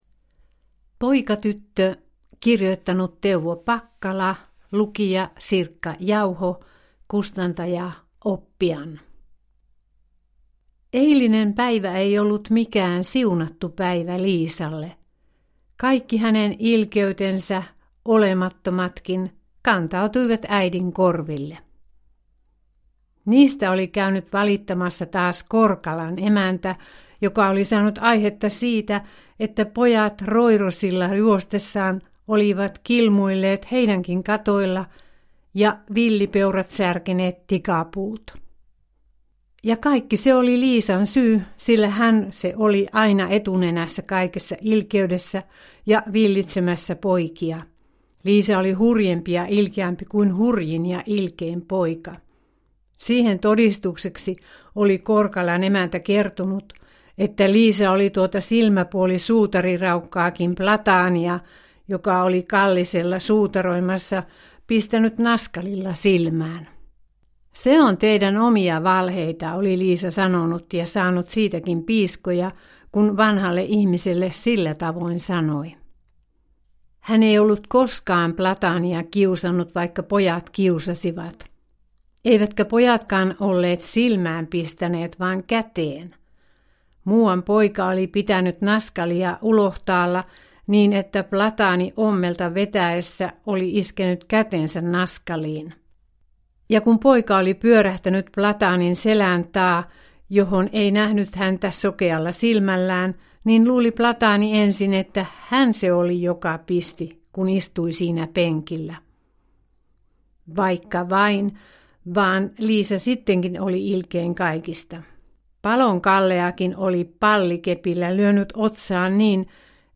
Poikatyttö – Ljudbok – Laddas ner